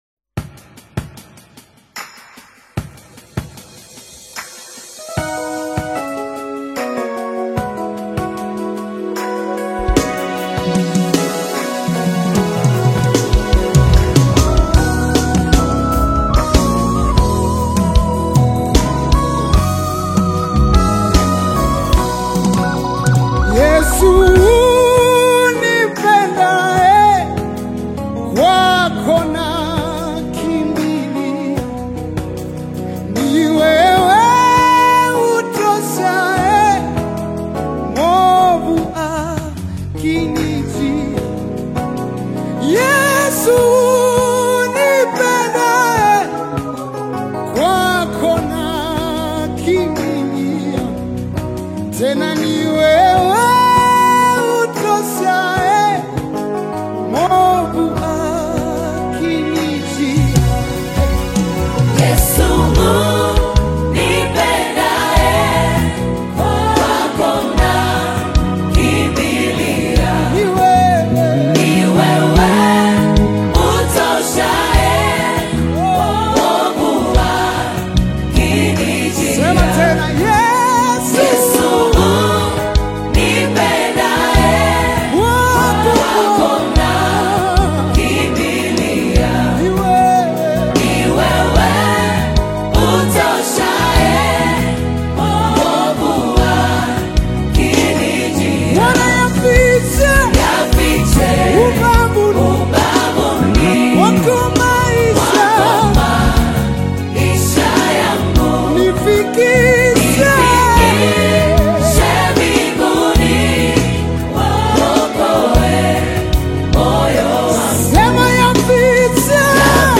soulful harmonies
emotive vocals
rich, resonant tones